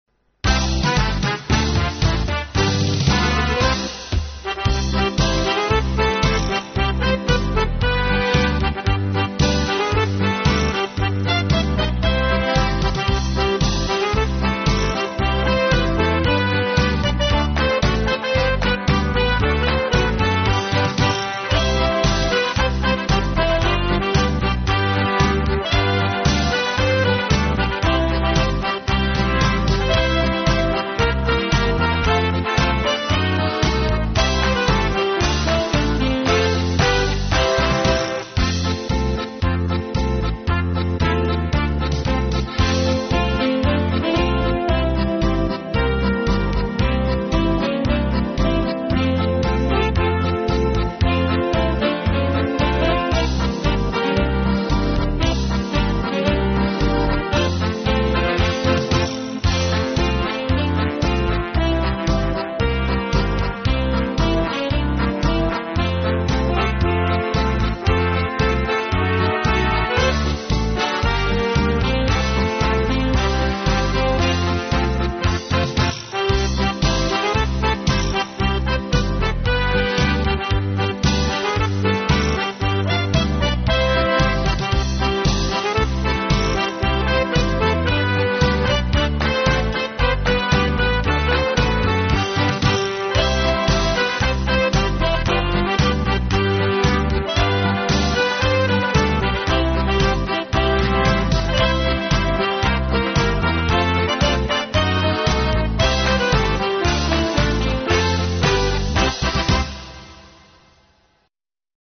Die Stücke I sing a Liad für di und Wien bleibt Wien sind in den Demos verkürzt wieder gegeben, wobei allerdings doch alle im Original vorkommenden Teile zu hören sind.